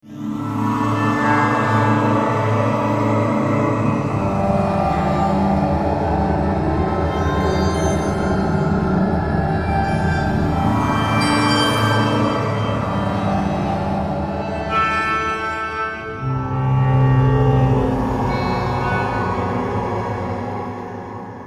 描述：孤独的不和谐的钥匙
Tag: 100 bpm Ambient Loops Piano Loops 1.21 MB wav Key : Unknown